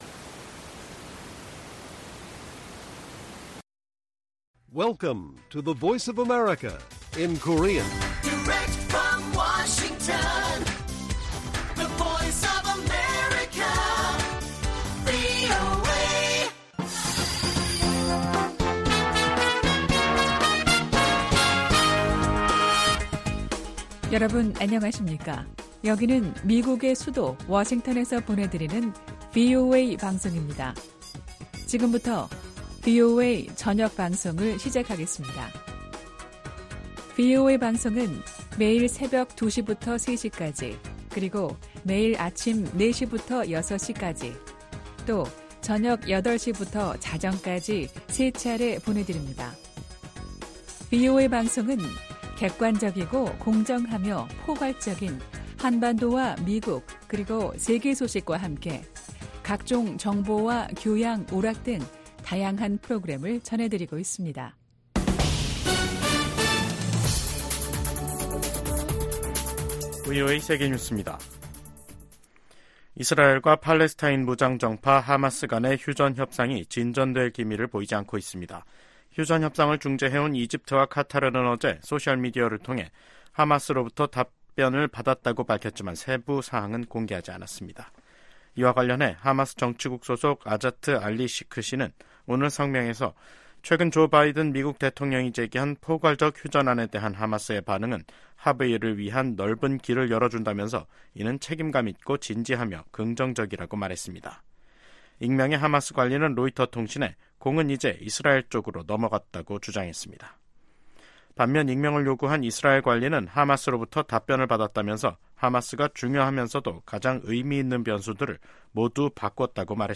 VOA 한국어 간판 뉴스 프로그램 '뉴스 투데이', 2024년 6월 12일 1부 방송입니다.